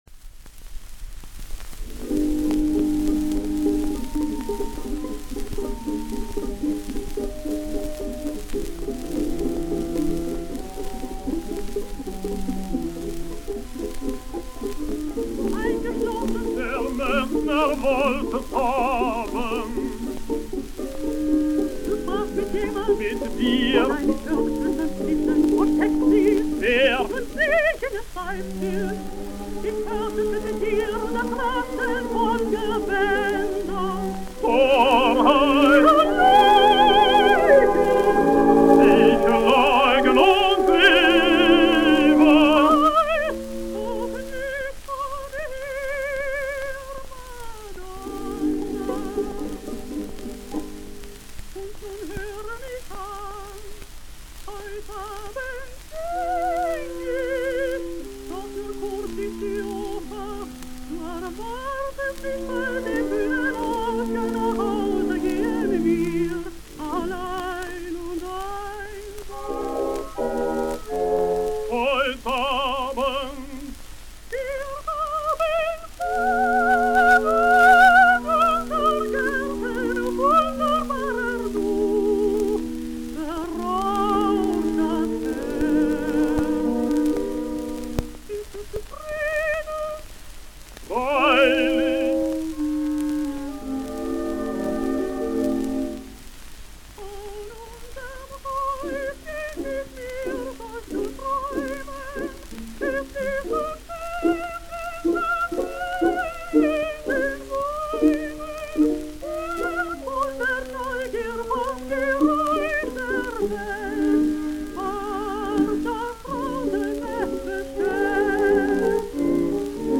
Mit deinen Augen, den wunderbaren, with Emmy Bettendorf